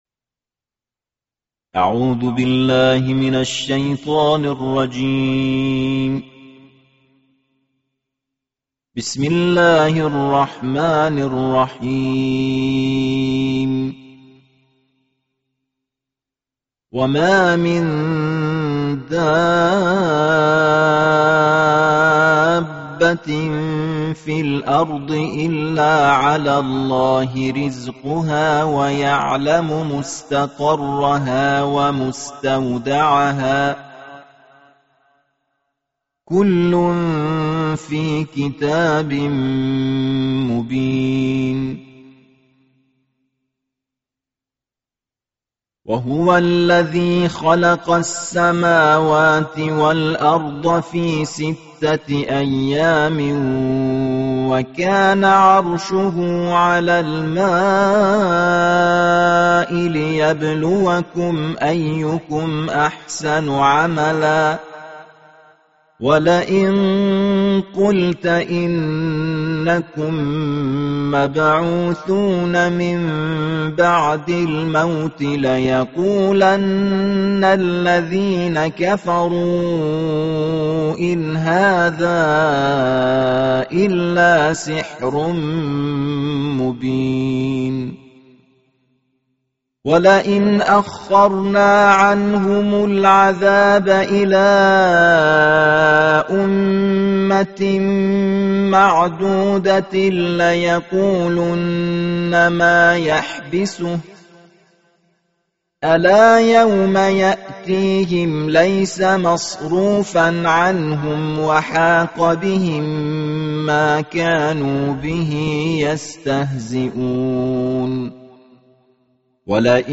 د روژې په دولسمه ورځ د قرآن کریم د دولسمې سپارې په زړه پورې تلاؤت